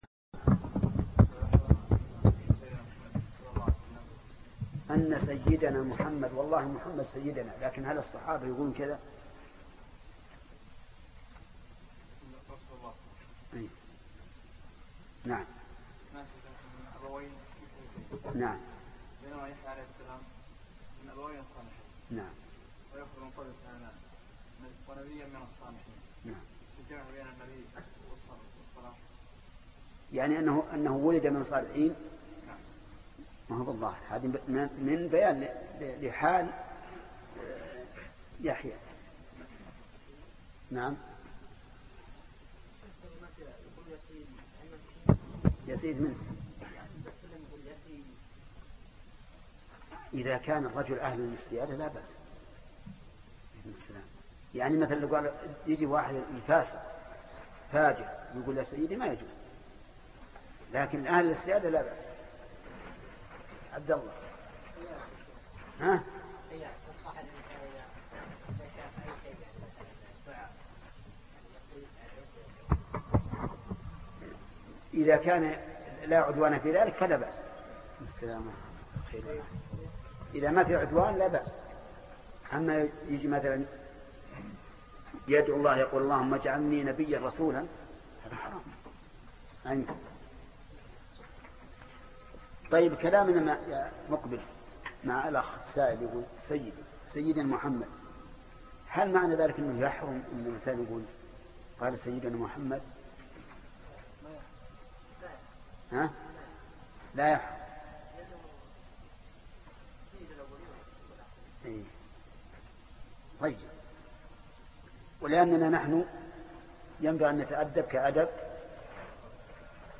الدرس 77 فوائد الأية 38 ( تفسير سورة آل عمران ) - فضيلة الشيخ محمد بن صالح العثيمين رحمه الله